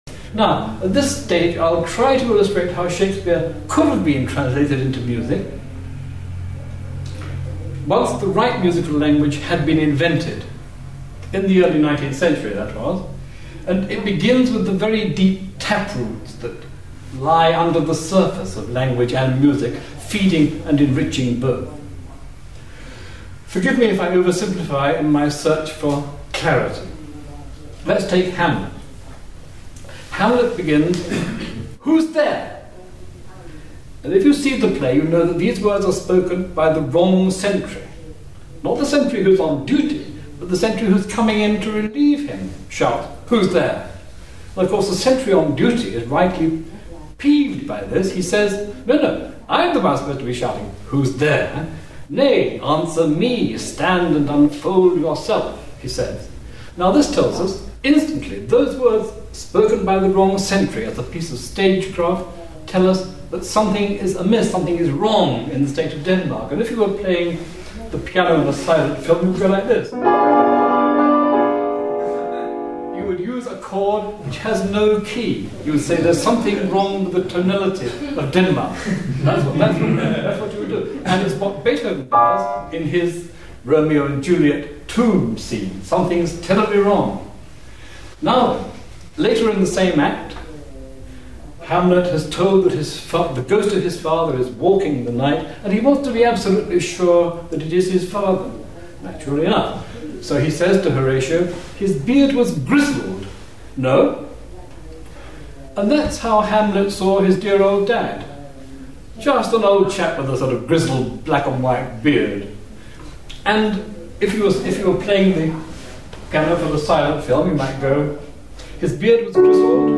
Lecture held at the William Walton Foundation, 9 September 1991